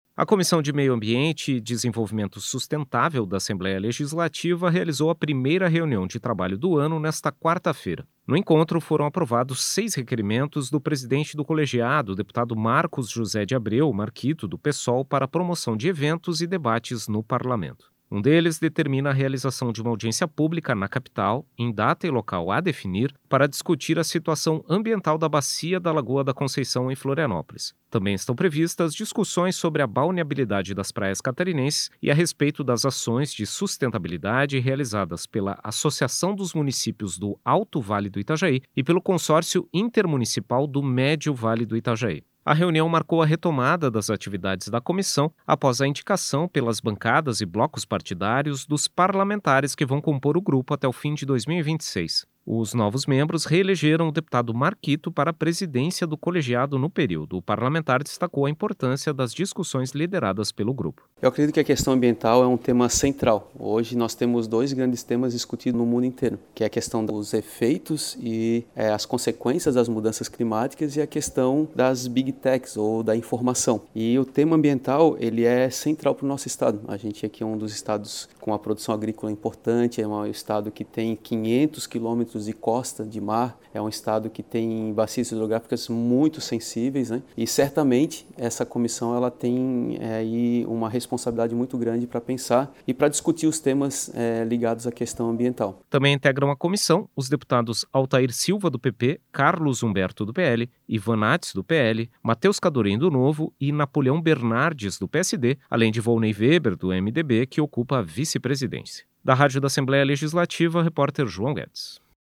Entrevista com:
- deputado Marcos José de Abreu - Marquito (Psol), presidente da Comissão de Meio Ambiente e Desenvolvimento Sustentável da Assembleia Legislativa.